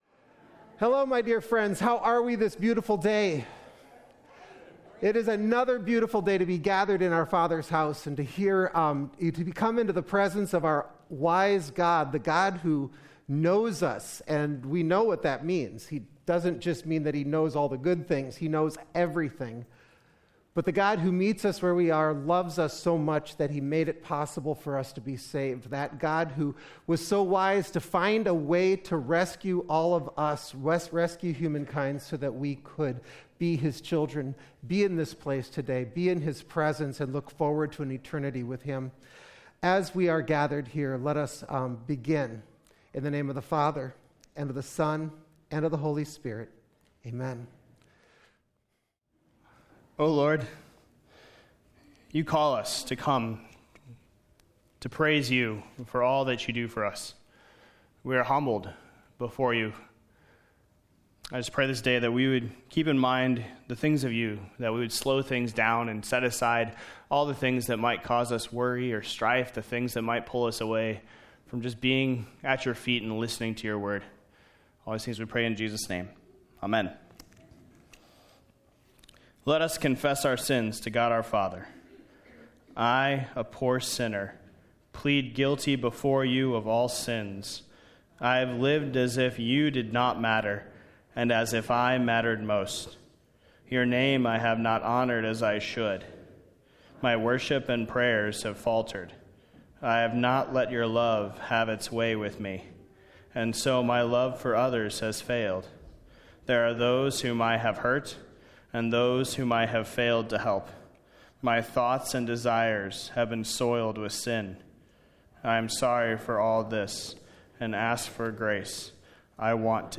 2024-March-3-Complete-Service.mp3